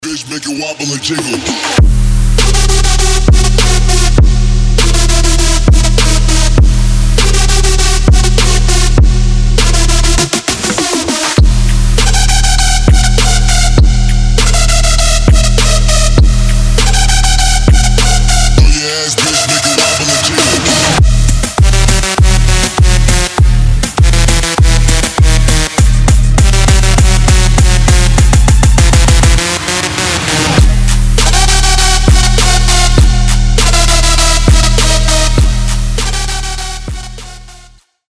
• Качество: 320, Stereo
мужской голос
громкие
мощные
Electronic
Trap
качающие